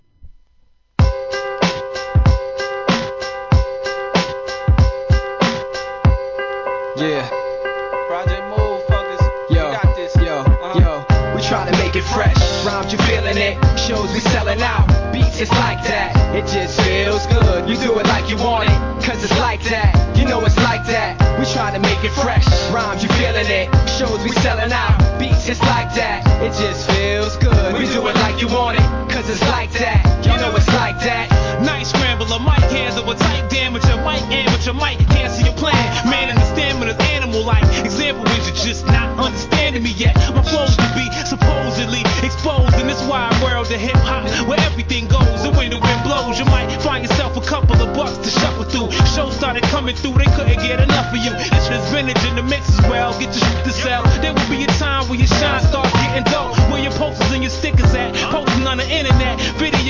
HIP HOP/R&B
ウッド・ベースに心地よいシンセラインが絡む極上RMEIX!!